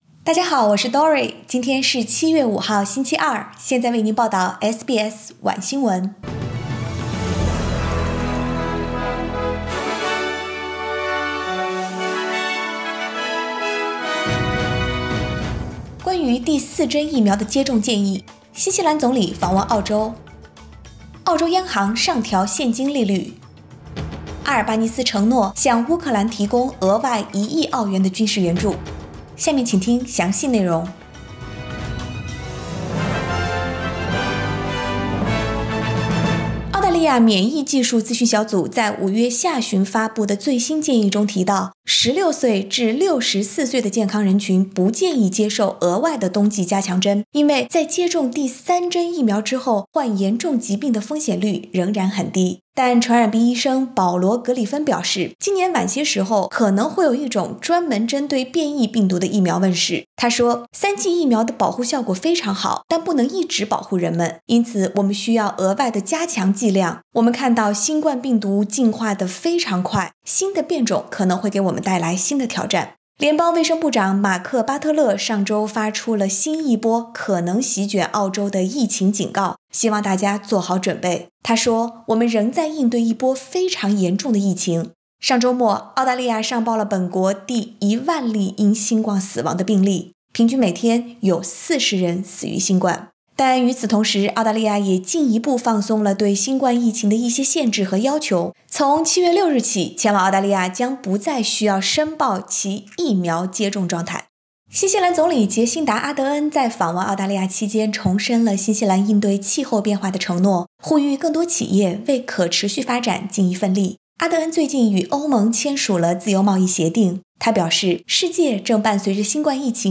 SBS晚新闻（2022年7月5日）